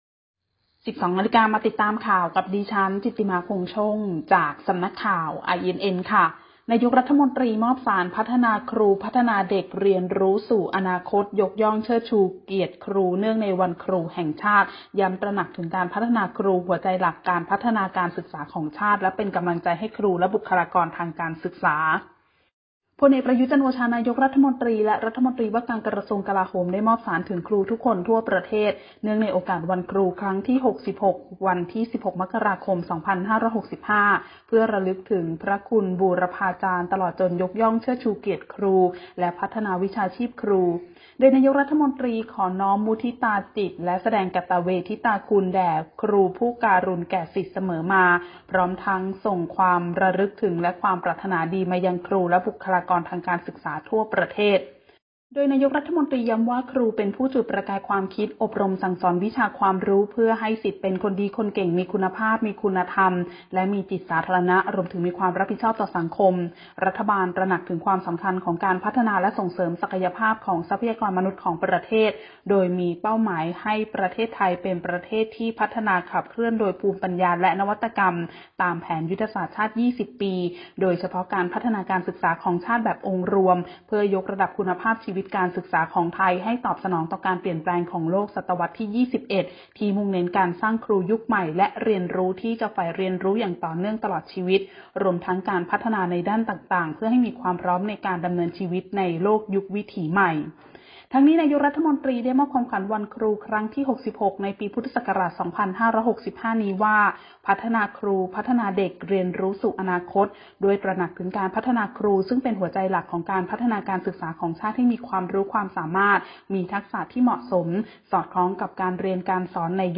ข่าวต้นชั่วโมง 12.00 น.